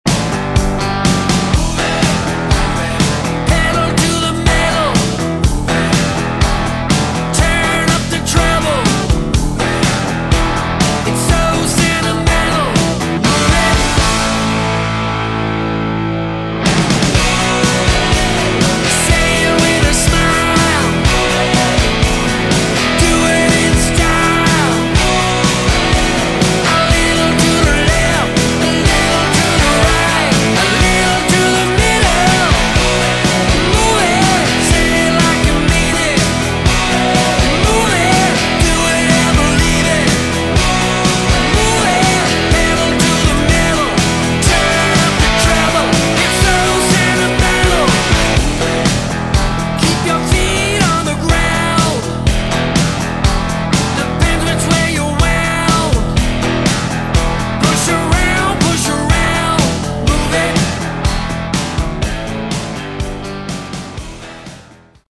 Category: Melodic Rock
guitars, bass, keyboards, backing vocals
lead vocals